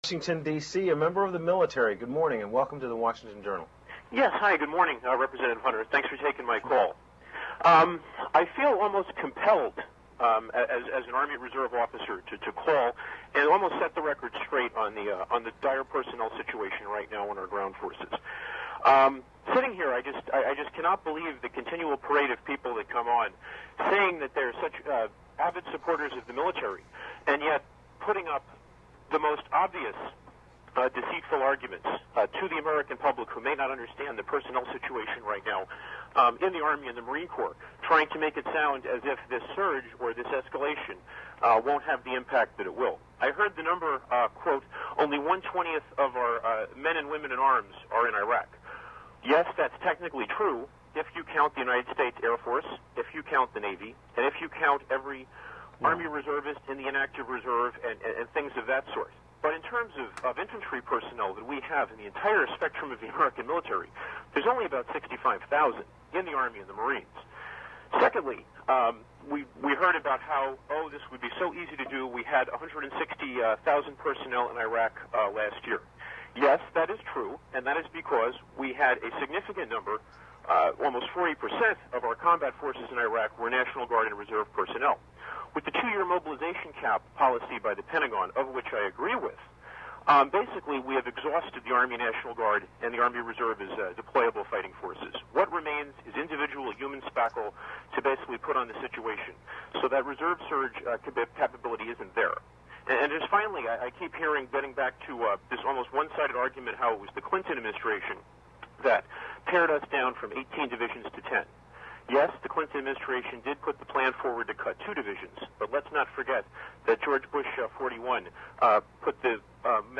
This morning on C-Span, they had a parade of Congressmen to talk about their feelings on Bush's plan to send 20,000 more troops to Iraq.
One of the callers was an Army Reserve Officer who made a statement about the actual condition of America's ground military - army and marines.  He sounded very credible to me.  Then as Duncan Hunter responded - first telling the officer that his numbers were absolutely wrong - but then he contradicted himself as he continued to drone on.
audio clip of the call.